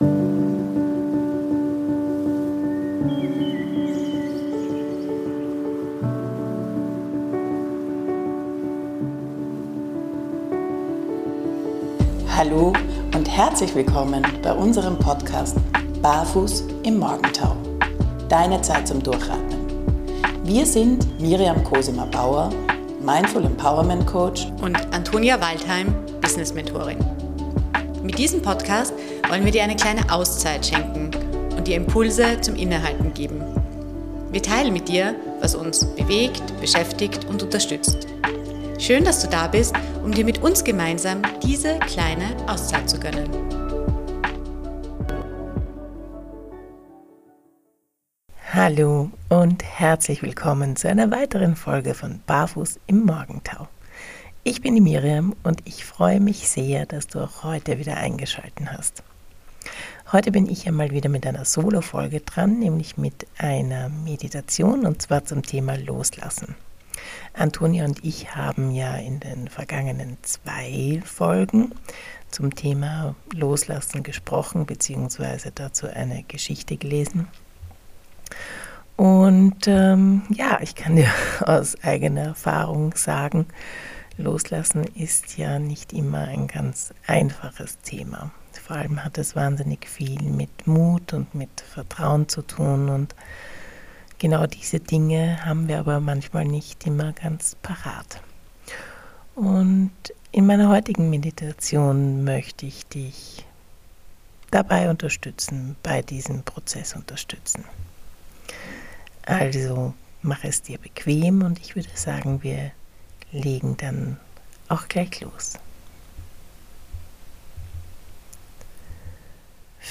In einer geführten Meditation verbinden wir uns mit dem Atem, öffnen unsere Hände und unseren Geist für das Neue, das wir nur empfangen können, wenn wir bereit sind, los zu lassen.